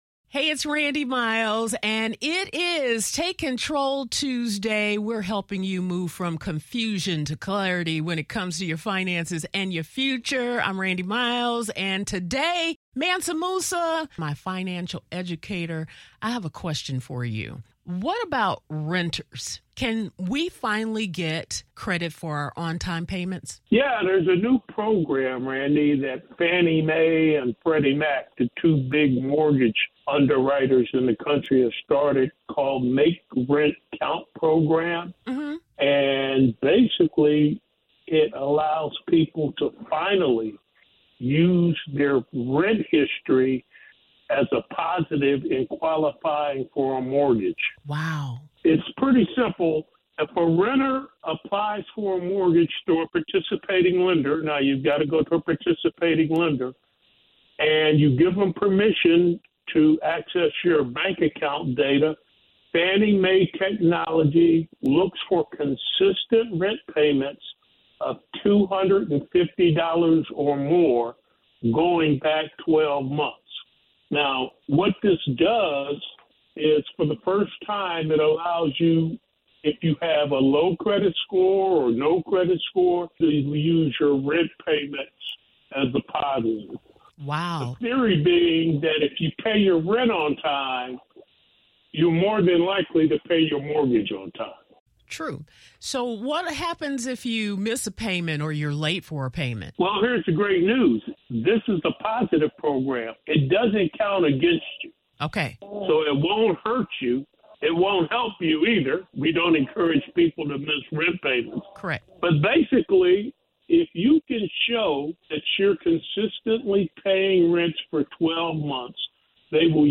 Good news for renters: your on-time rent payments could now help you qualify for a mortgage! In a recent conversation